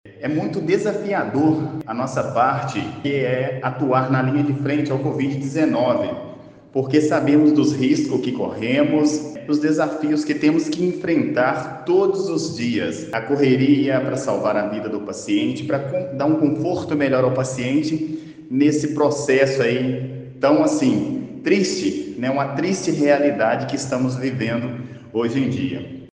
Em entrevista a Rádio Manhuaçu e jornal Tribuna do Leste